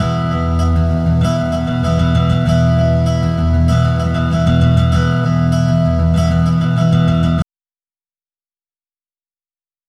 Hallo, ich habe ein älteres Gitarren-Lick (Strumming-Sample) gefunden, dass ich gerne mittels VST "nachbauen" möchte um es komfortabler verwenden zu können...
Ist nur 16 Bit wenn ich das richtig sehe und musikalisch auch nix Besonderes, daher glaube ich, das das gut zu rekonstruieren ist.